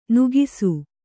♪ nugisu